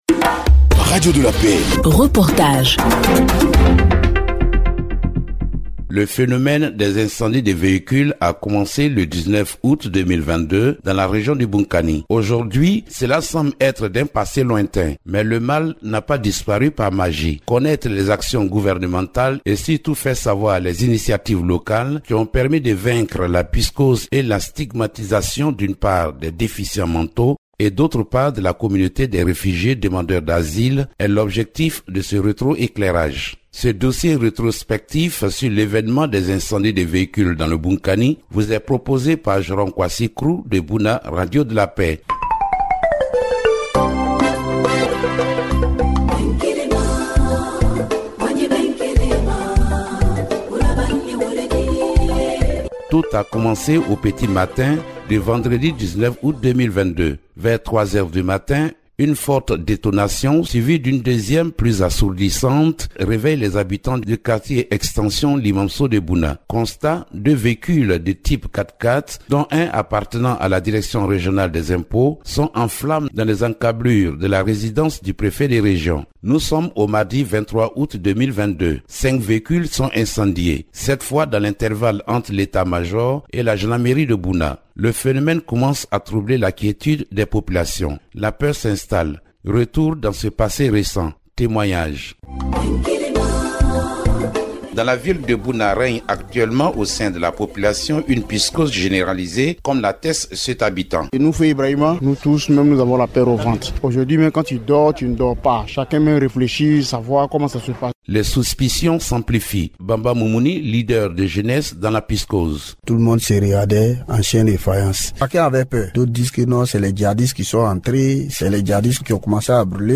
reportage-retro-eclairage-sur-les-incendies-de-vehicules-dans-le-bounkani.mp3